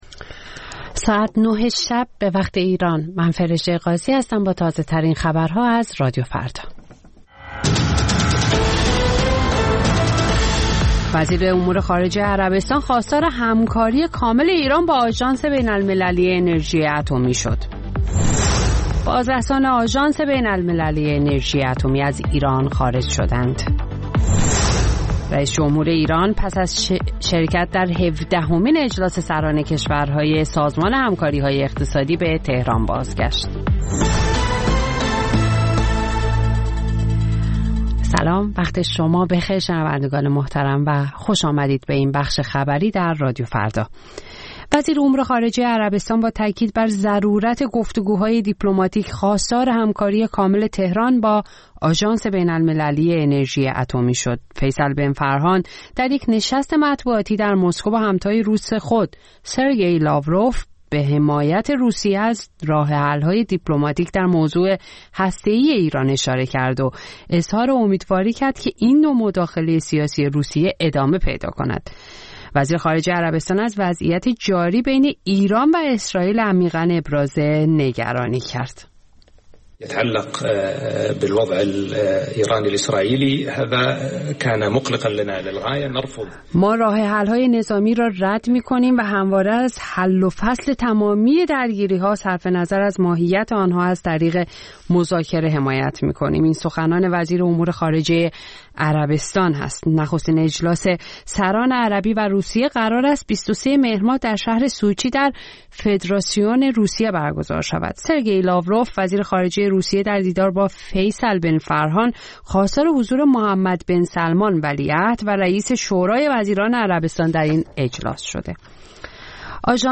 سرخط خبرها ۲۱:۰۰
پخش زنده - پخش رادیویی